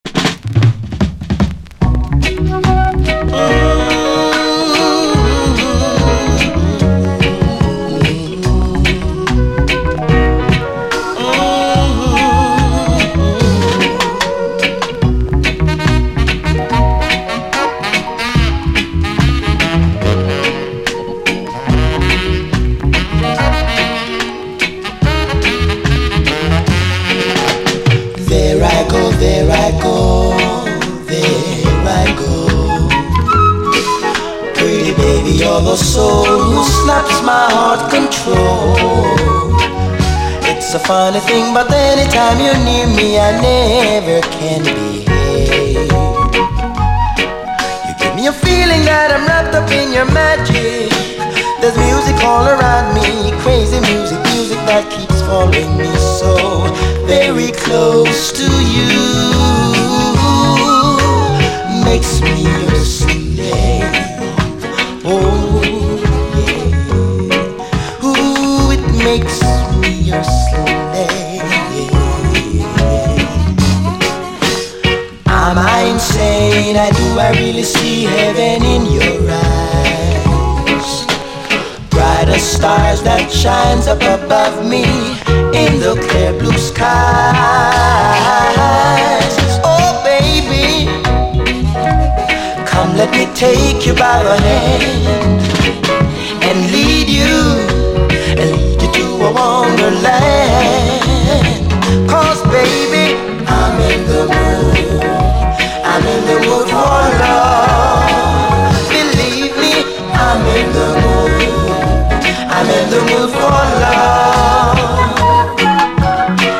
REGGAE, 7INCH
70'Sスウィート・レゲエ・クラシック！
中盤に周回プチノイズ有。
フルートとピアノとサックスがムーディーな名演。